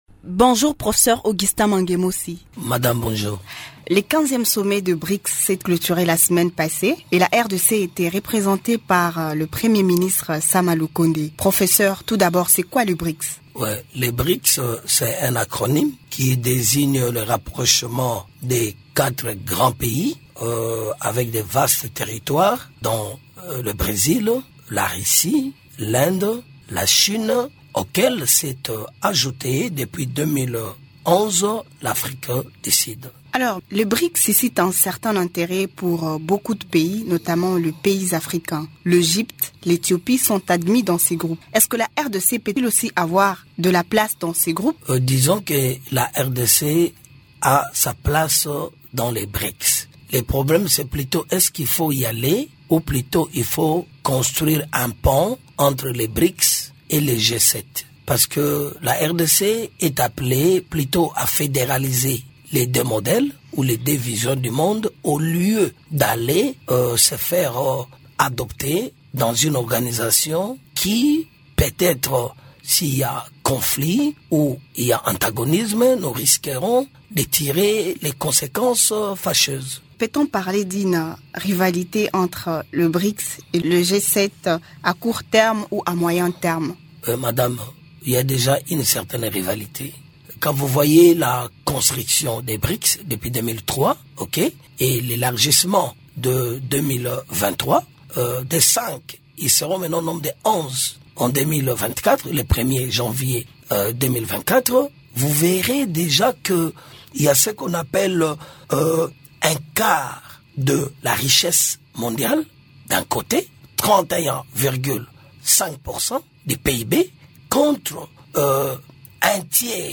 Invité de Radio Okapi, il propose que le pays puisse construire un pont entre les BRICS et le G7.